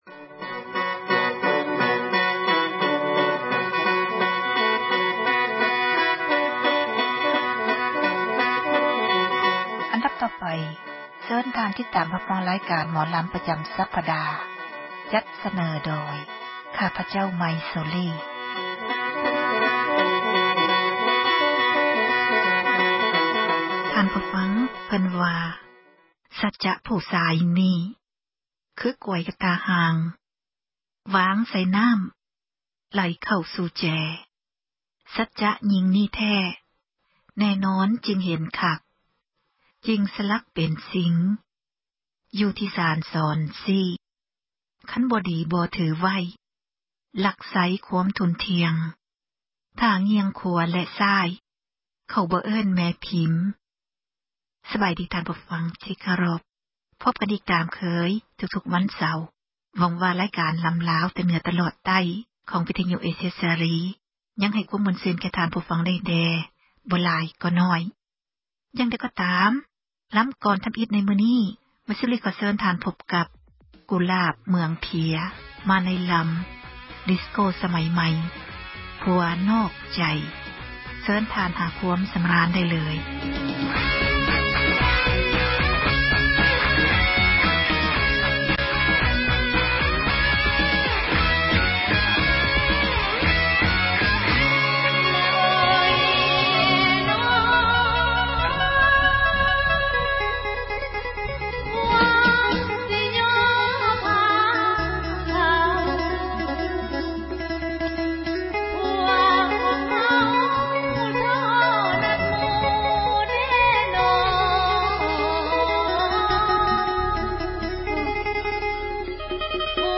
ຣາຍການ ໝໍລຳລາວ ປະຈຳ ສັປດາ ສເນີໂດຍ